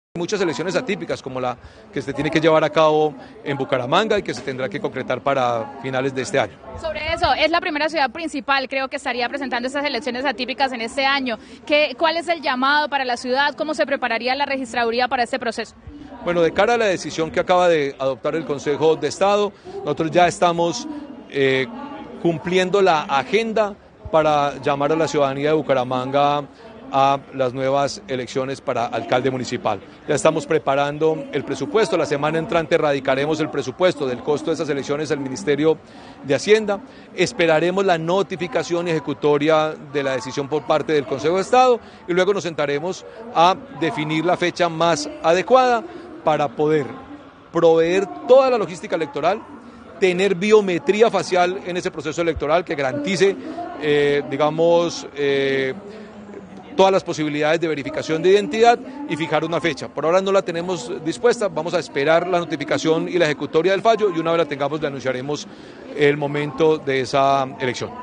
Hernán Penagos, registrador nacional